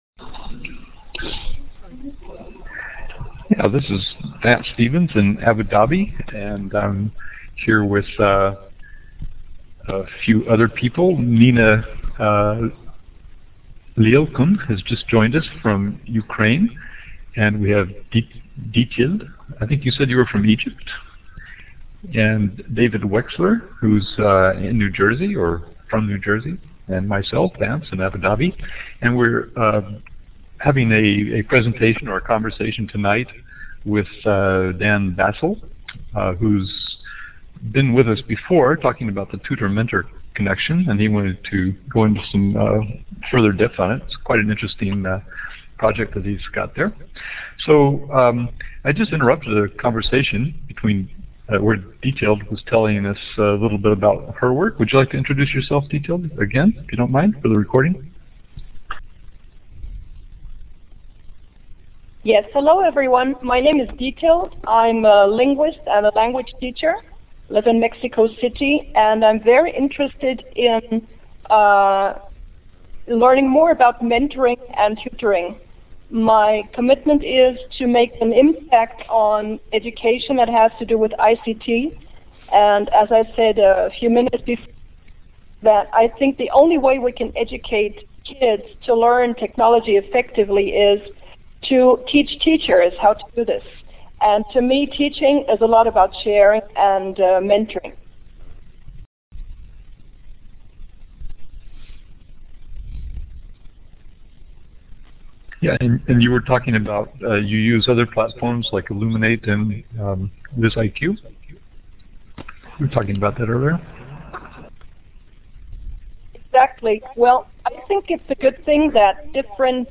This is the recording made at the Plenery presentation in Marrakech.